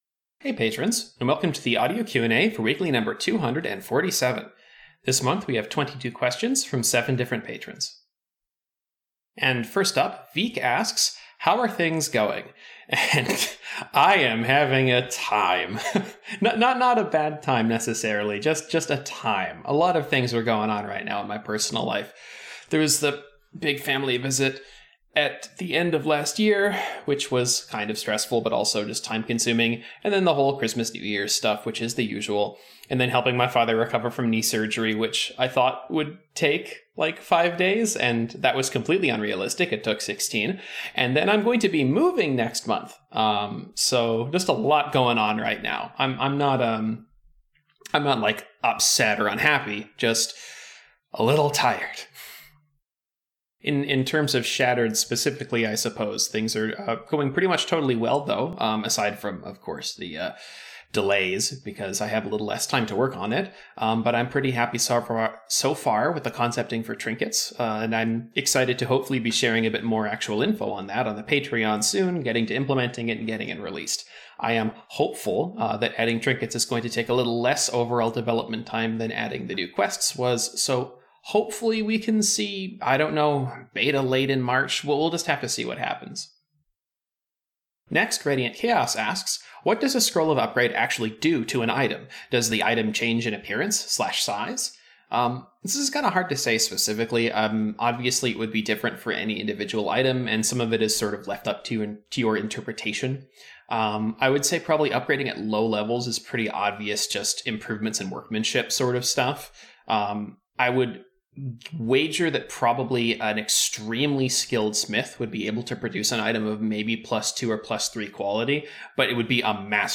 This weekly also includes an audio Q&A!